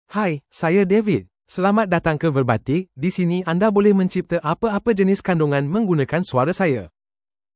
MaleMalay (Malaysia)
David is a male AI voice for Malay (Malaysia).
Voice sample
Male